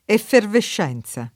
effervescenza [ effervešš $ n Z a ] s. f.